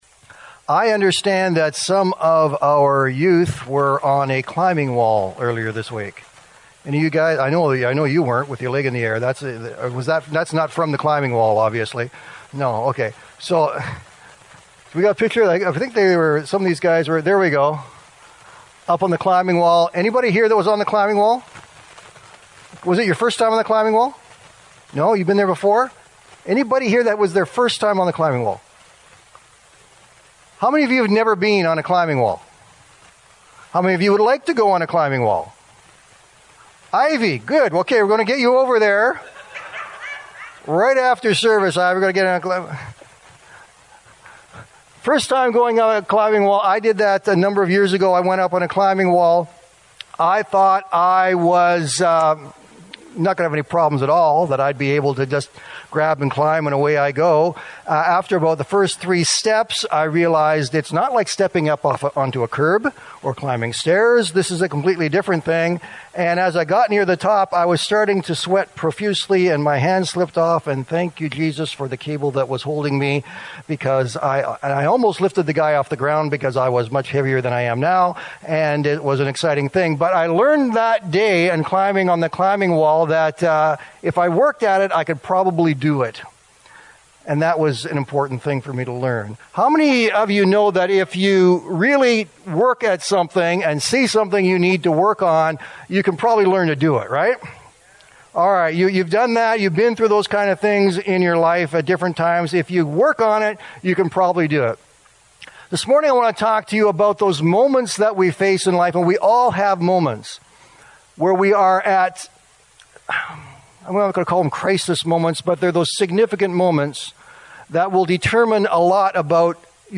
Sermons | Crossroads Community Church of the Christian and Missionary Alliance in Canada